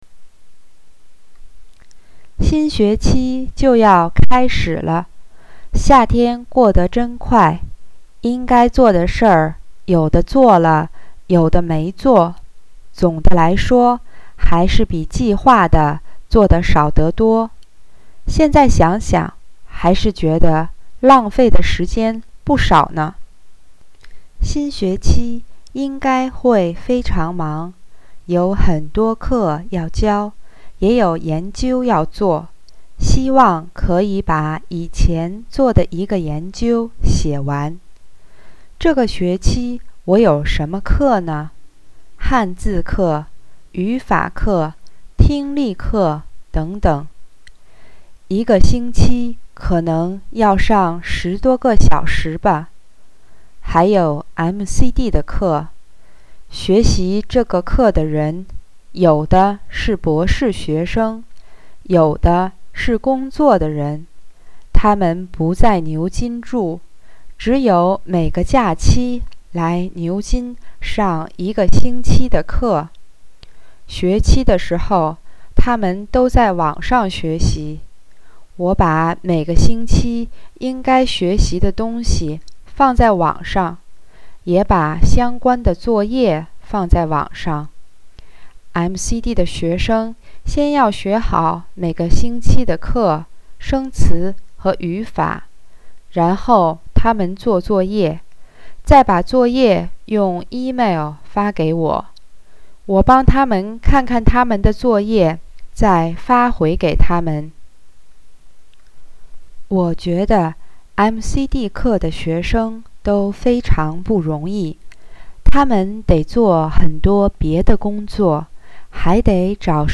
Listening Comprehension
listening normal.mp3